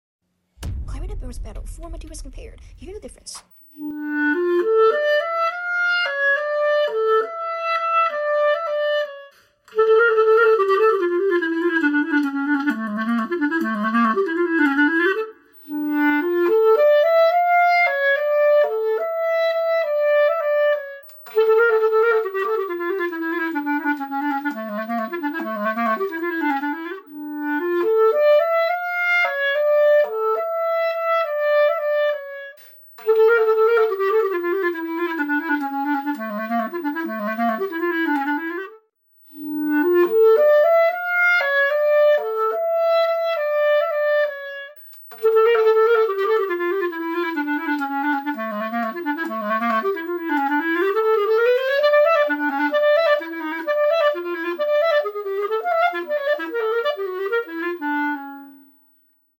*Clarinet Barrels Battle: 4 Materials